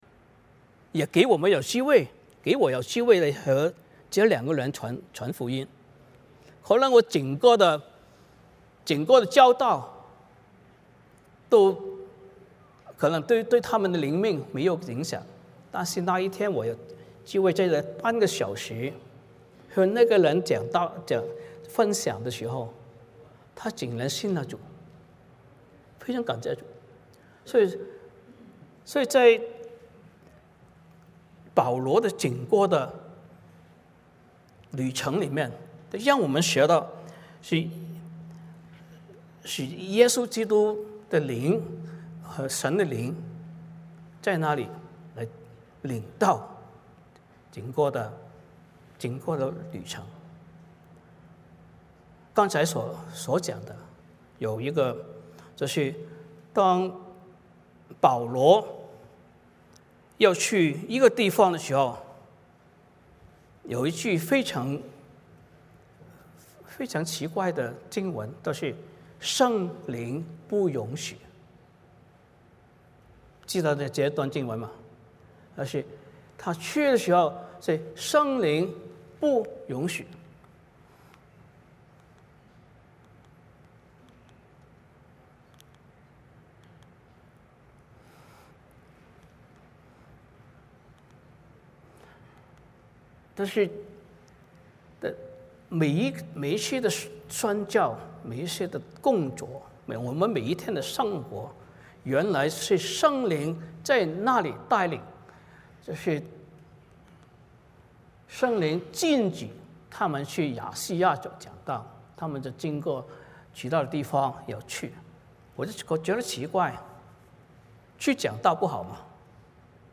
Passage: 使徒行传 16:1-15 Service Type: 主日崇拜 欢迎大家加入我们的敬拜。